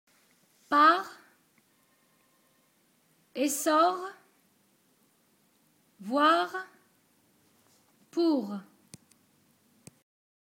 En position finale on prononce le r :
• s'il est précédé de a, o, i, u, comme dans : par, essor, voir, pour...